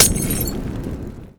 MolotovImpact.wav